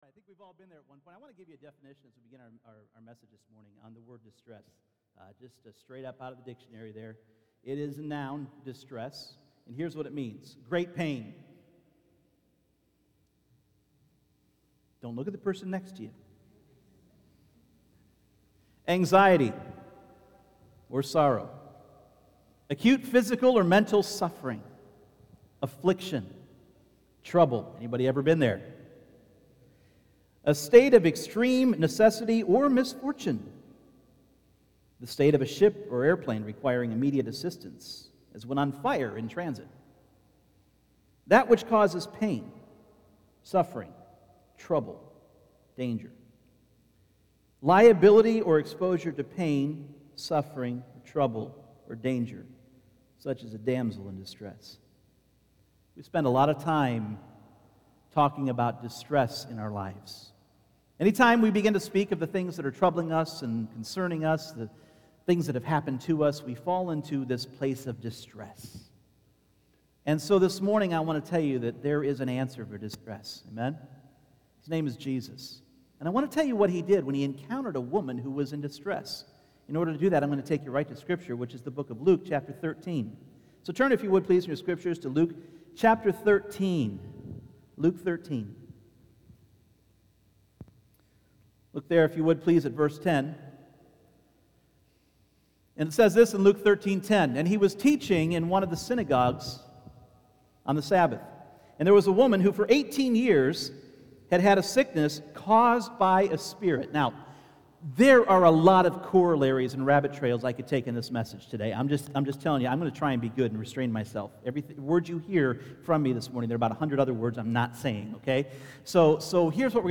Spiritual Warfare Service Type: Sunday Morning Jesus gave us POWERFUL tools for BINDING and LOOSING in our prayer.